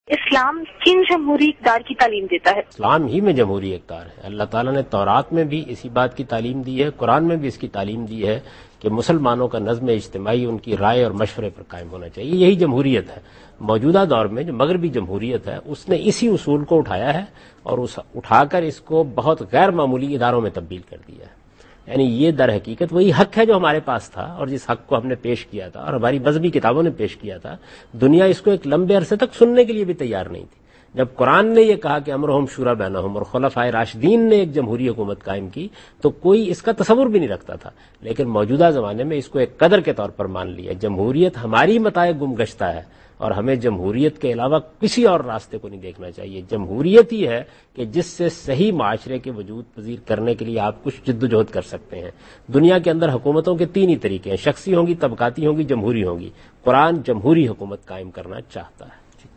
Javed Ahmad Ghamidi answers a question regarding "Islam and Democracy" in program Deen o Daanish on Dunya News.
جاوید احمد غامدی دنیا نیوز کے پروگرام دین و دانش میں اسلام اور جمہوریت سے متعلق ایک سوال کا جواب دے رہے ہیں۔